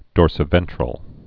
(dôrsĭ-vĕntrəl) or dor·so·ven·tral (-sō-)